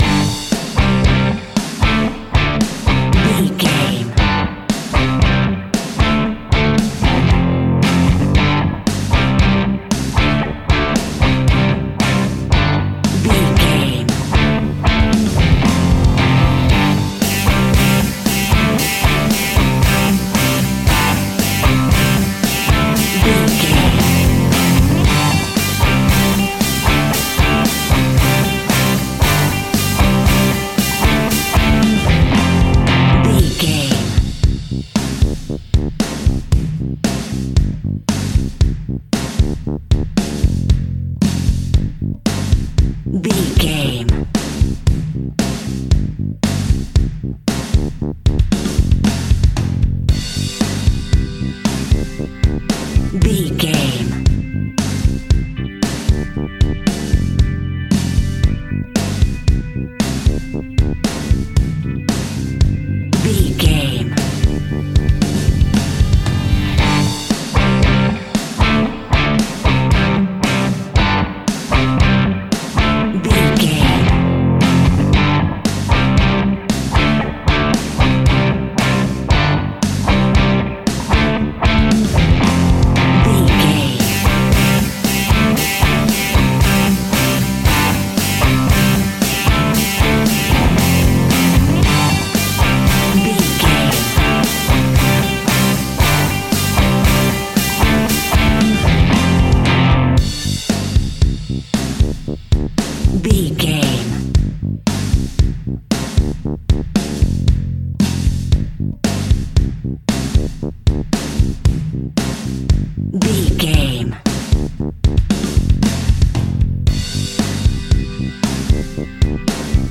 Epic / Action
Ionian/Major
hard rock
heavy metal
blues rock
instrumentals
rock guitars
Rock Bass
heavy drums
distorted guitars
hammond organ